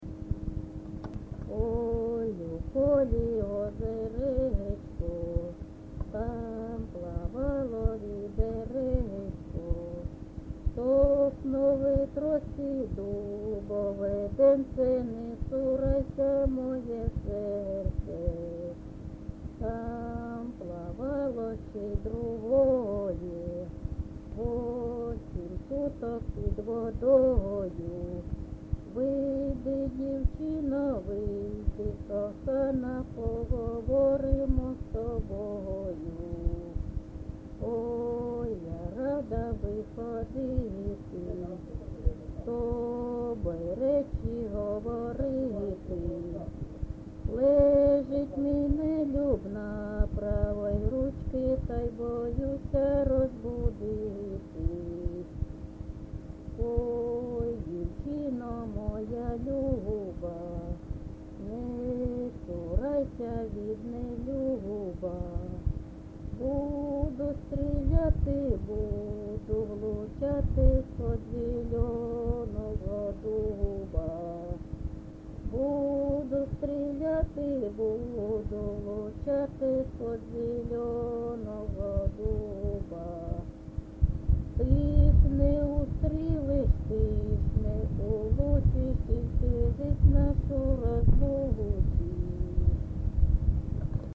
ЖанрПісні з особистого та родинного життя
Місце записус. Хрестівка, Горлівський район, Донецька обл., Україна, Слобожанщина